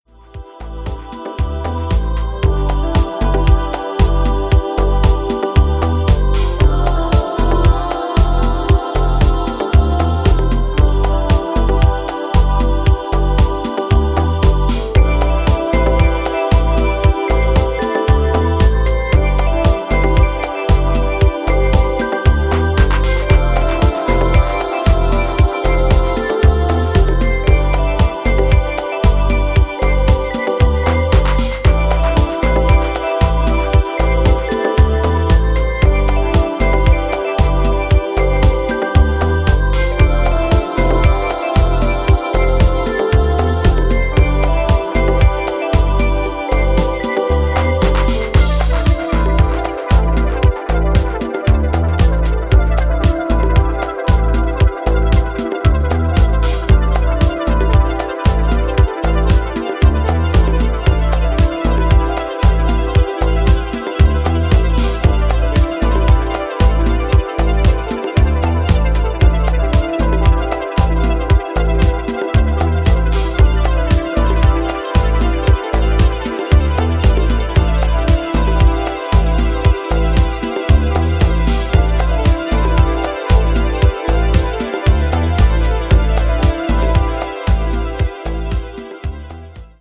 ジャンル(スタイル) HOUSE / BALEARIC